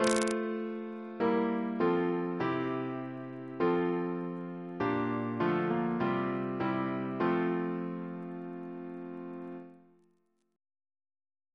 Single chant in F Composer: Peter Fussell (1750-1802) Reference psalters: OCB: 194; PP/SNCB: 38